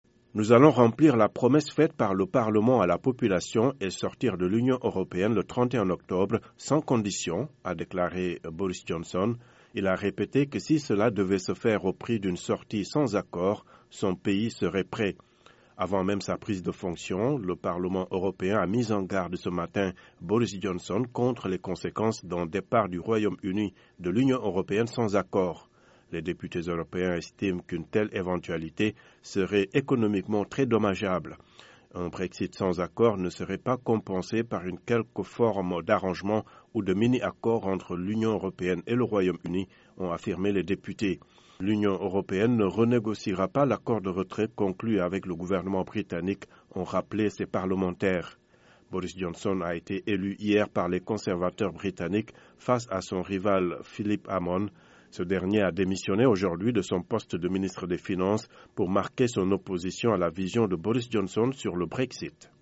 Le nouveau Premier ministre britannique Boris Johnson a promis de sortir le Royaume-Uni de l'Union européenne le 31 octobre sans conditions, lors de son premier discours devant Downing Street.